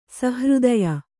♪ sahřdaya